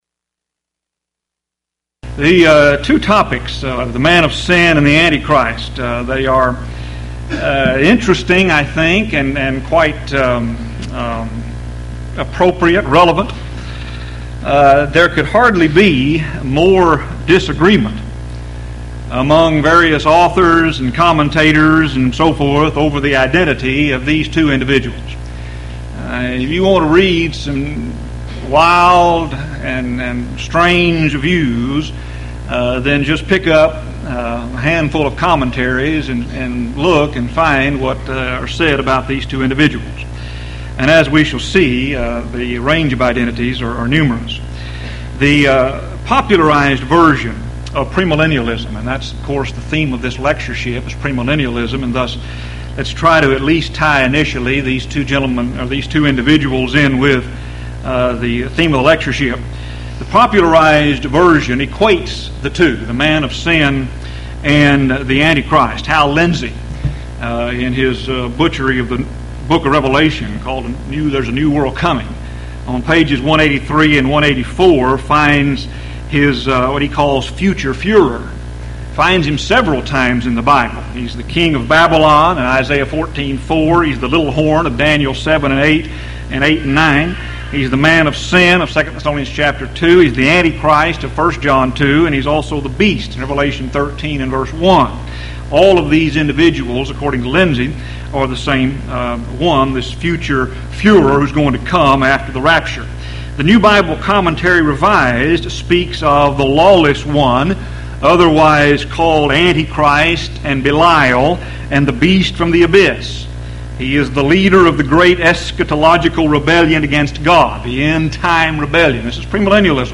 Houston College of the Bible Lectures Event: 1997 HCB Lectures Theme/Title: Premillennialism